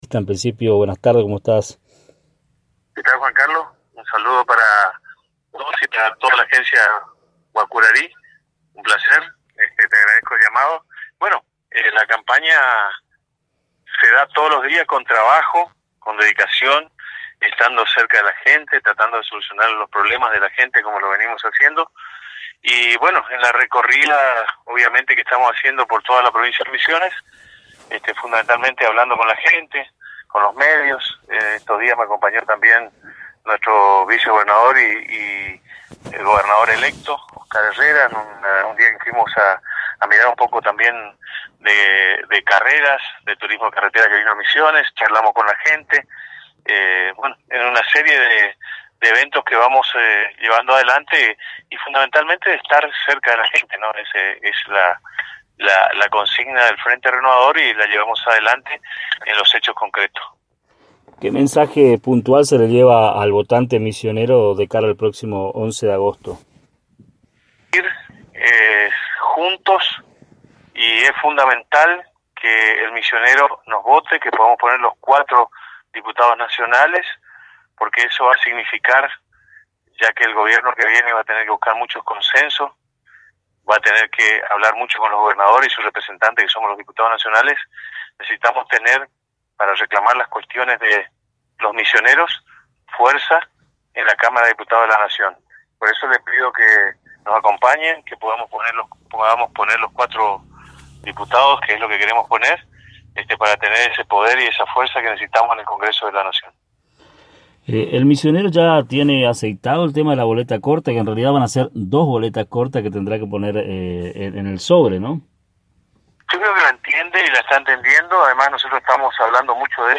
En dialogo Exclusivo con Agencia de Noticias Guacurarí, el candidato a primer Diputado Nacional Diego Sartori, comentó sobre su actividad en inicio oficial de la campaña.